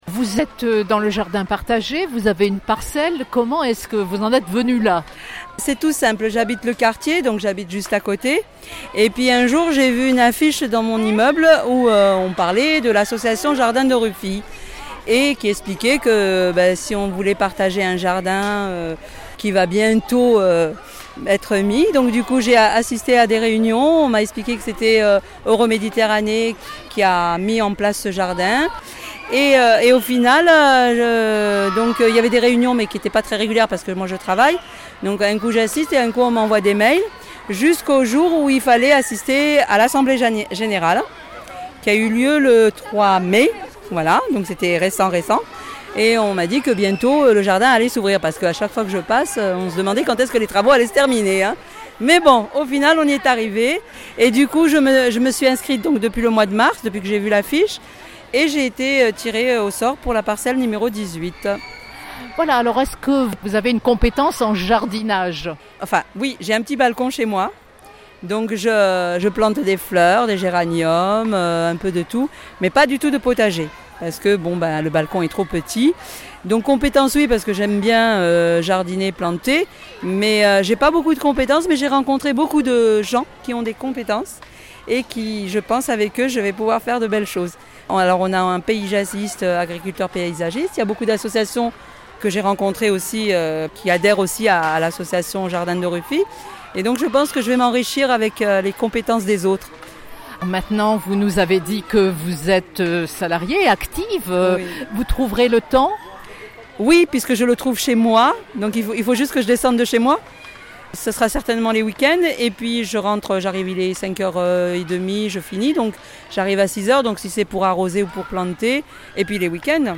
Au 14, rue de Ruffi dans le 3e arrondissement de Marseille, entre plants de fraises et barbe à papa, les habitants du quartier se pressaient pour cette fête de quartier organisée pour l’inauguration des Jardins de Ruffi à la fois jardin d’enfant et jardins partagés.